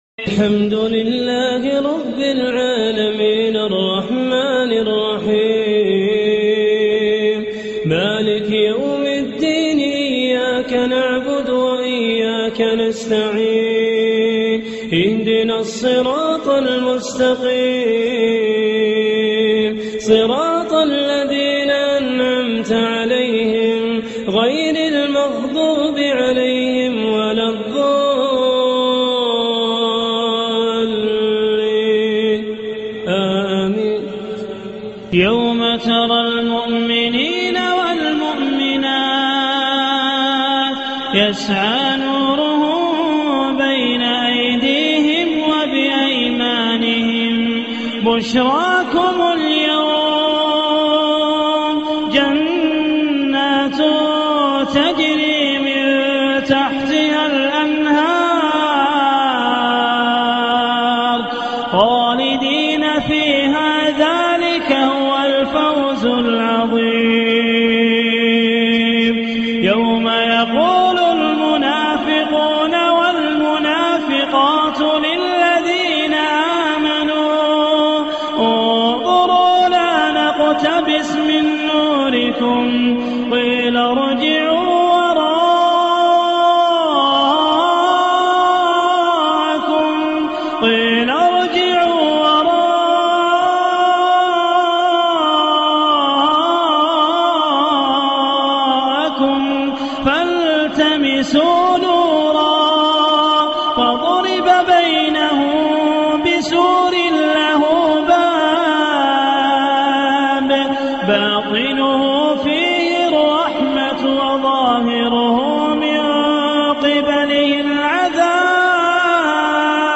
تلاوات